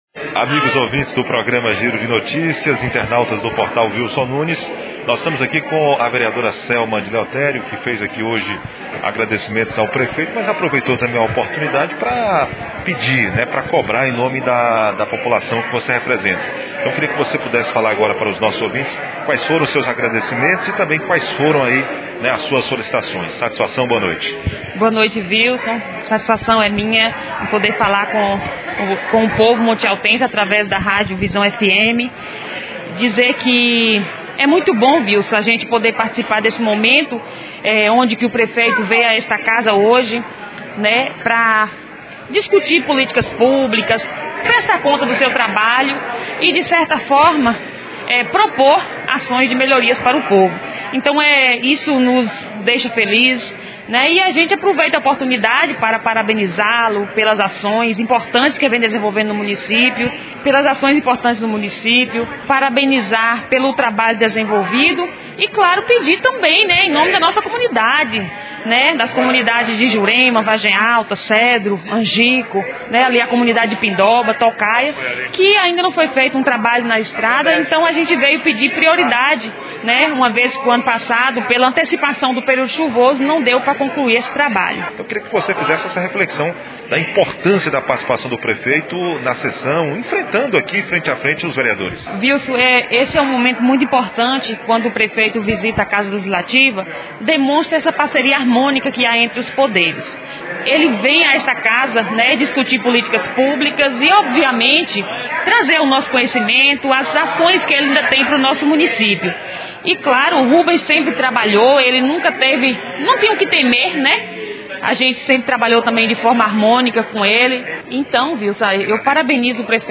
Ao final da sessão, a reportagem da Rádio Visão FM conversou com os vereadores Selma de Leotério, Zé Anísio, Patrick Show, Patrícia do Rancho e o prefeito Manoel Rubens.